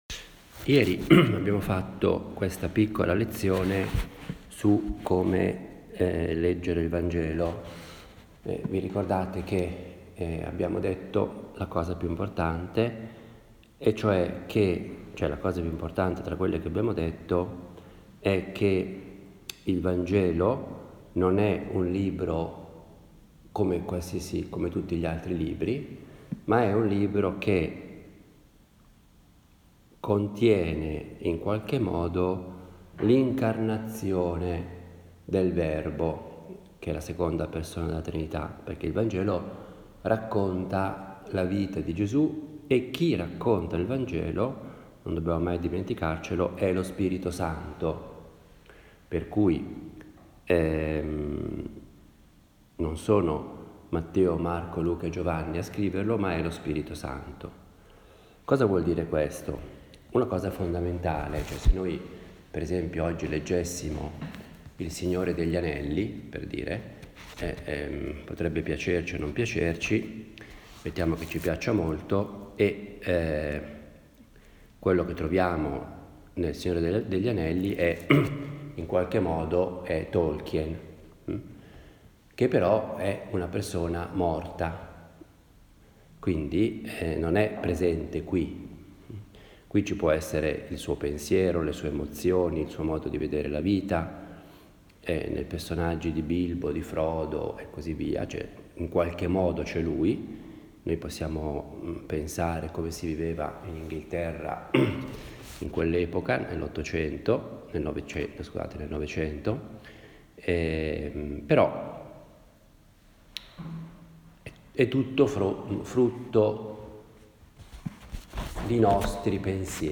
Una meditazione predicata durante un ritiro con ragazzi giovani
Ha il carattere piano, proprio di una conversazione familiare e io la intendo come il mio dialogo personale – fatto ad alta voce – con Dio, la Madonna, ecc. In genere do un titolo alle meditazione e cerco di fare molto riferimento alla scrittura, in particolare al vangelo. Le meditazioni che si trovano sul blog sono semplici registrazioni – senza nessuna pretesa particolare – di quelle che faccio abitualmente.